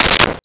Sword Whip 01
Sword Whip 01.wav